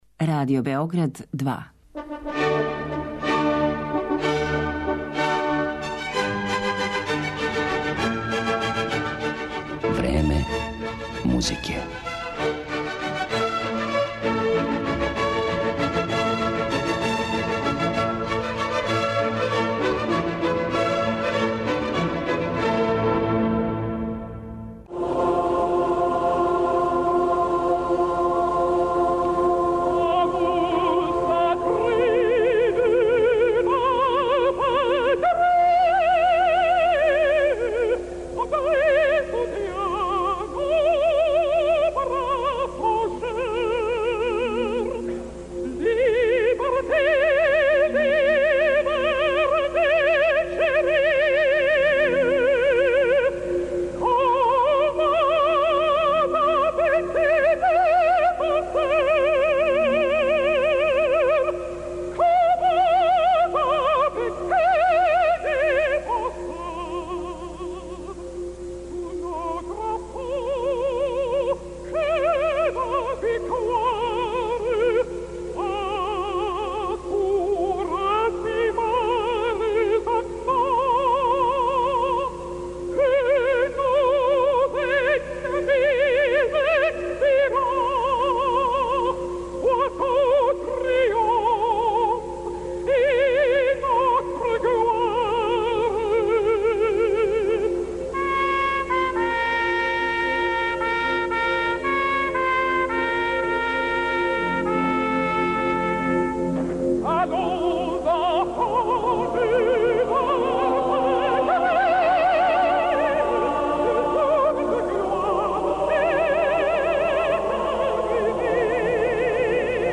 Чућете причу о овој свима познатој песми: о томе како је настала у доба француске буржоаске револуције, како је постала национална химна Француске и како су је, у својим делима, користили многи композитори уметничке, али и комерцијалне музике. Између осталих, у емисији ће бити емитоване и композиције из пера Роберта Шумана, Петра Иљича Чајковског, Ђоакина Росинија и Клода Дебисија.